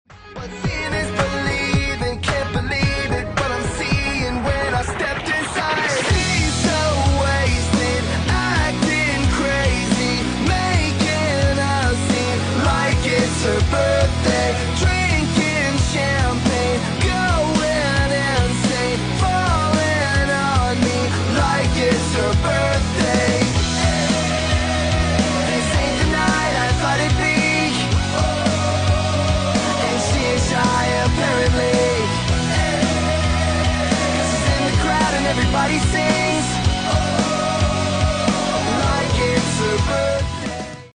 a playful, high-energy anthem
pop-punk edge. Catchy, fun, and built for sing-alongs